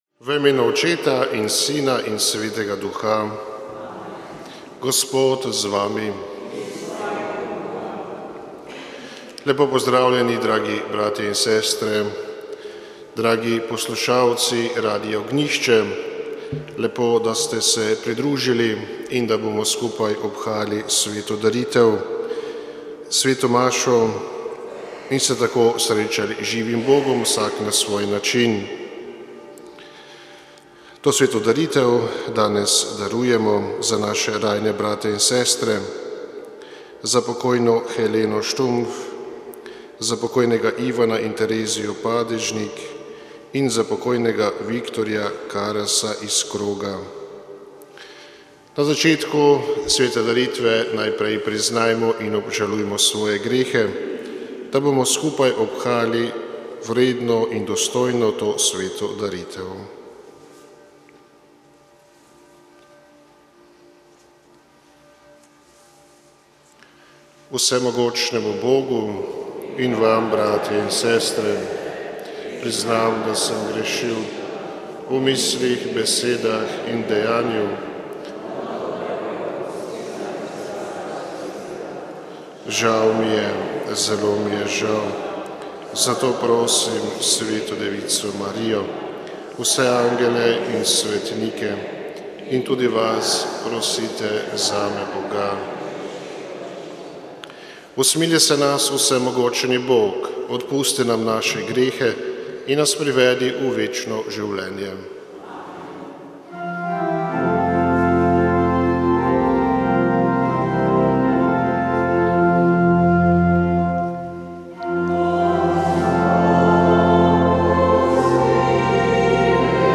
Sveta maša
Sv. maša iz stolne cerkve sv. Nikolaja v Murski Soboti 25. 4.